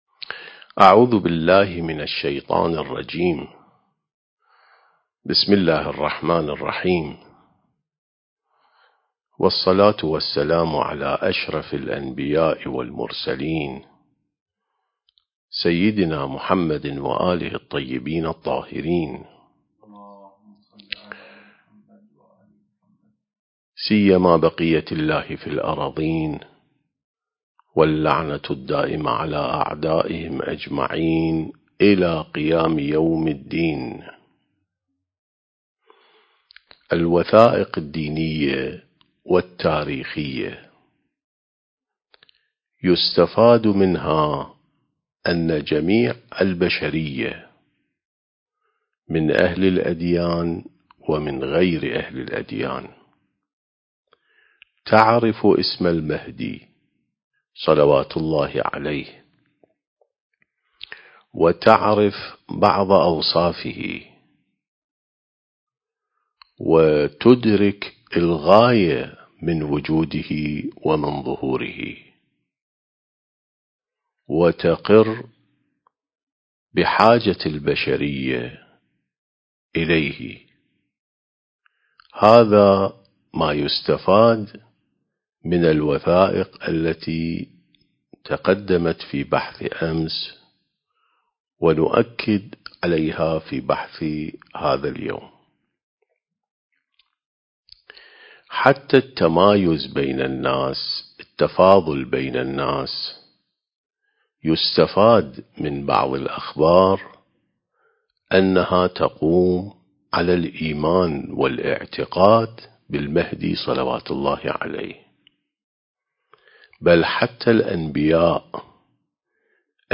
سلسلة محاضرات عين السماء ونهج الأنبياء (2) التاريخ: 1443 للهجرة